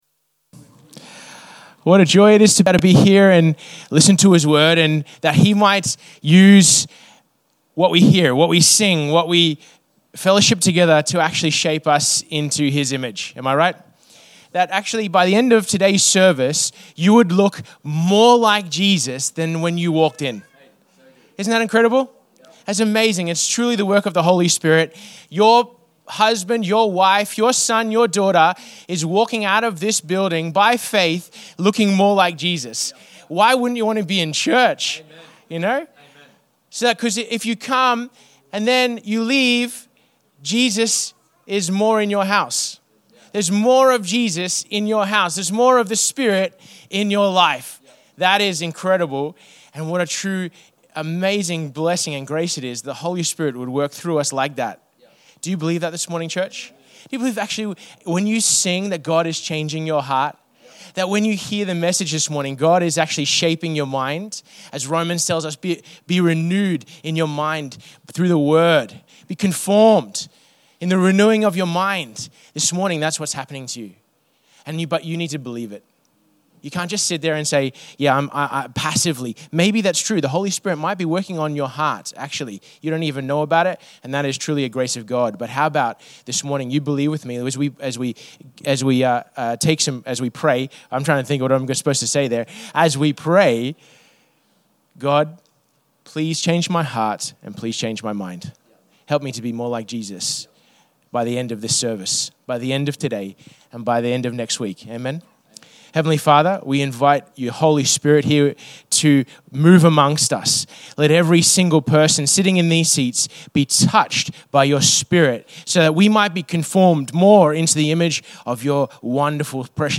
Infinity Church Podcast - English Service | Infinity Church
Current Sermon